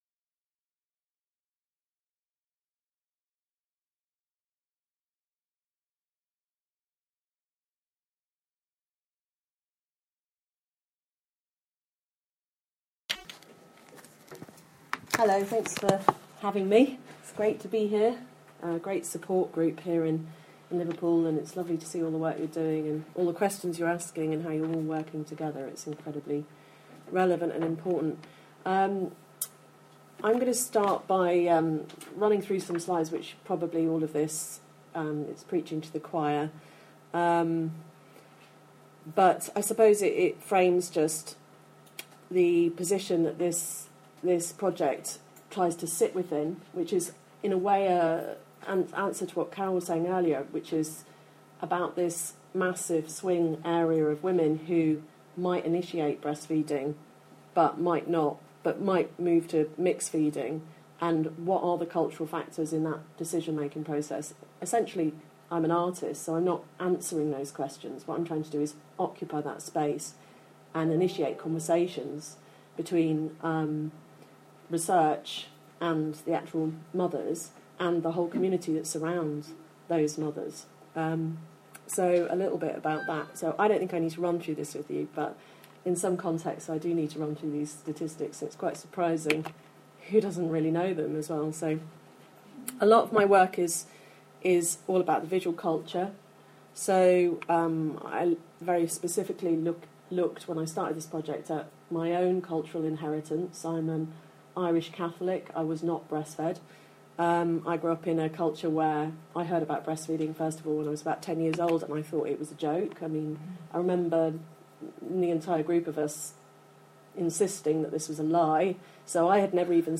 Overcoming the Cultural Barriers to BreastfeedingLiverpool Collaborative Research Group Presentation
Presentation-to-Collaborative-Research-Group-2020.mp3